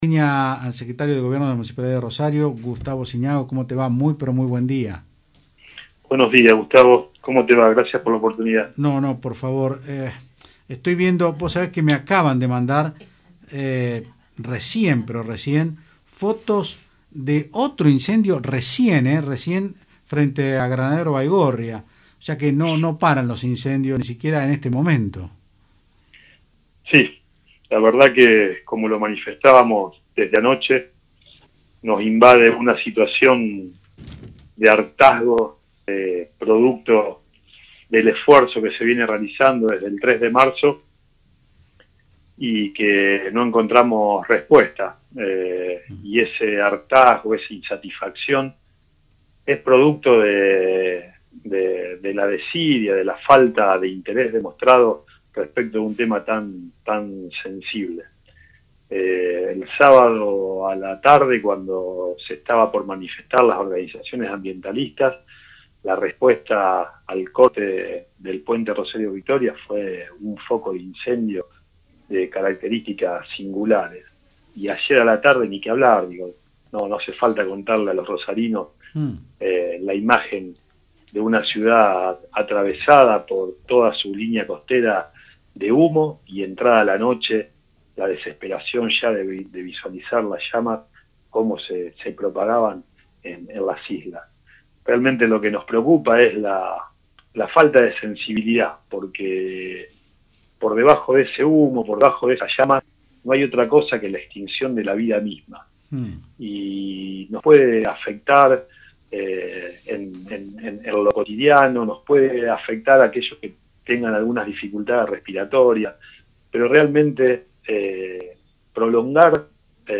El secretario de Gobierno de la Municipalidad, Gustavo Zignago dijo en Otros Ámbitos (Del Plata Rosario 93.5) que desde la Municipalidad no van a abandonar la tarea de buscar soluciones y responsables en los incendios de las islas.